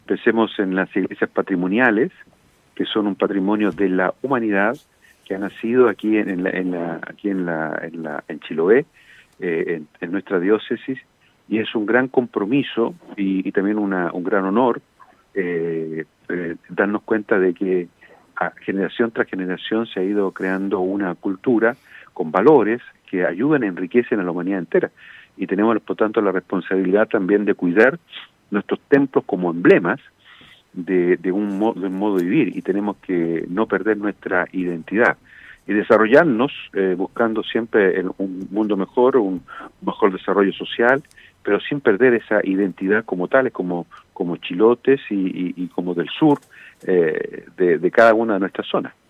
En el marco de esta conmemoración, conversamos en Radio Estrella del Mar, con Monseñor Juan María Agurto, quien comenzó haciendo un repaso por los capítulos iniciales en la historia de esta Diócesis.